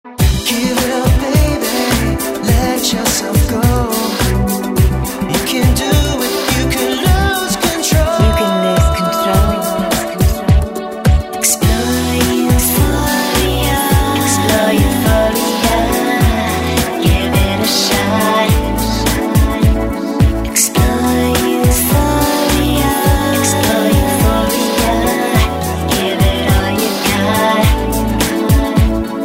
fusing elements of pop, rock, alternative & euro
seductive, uptempo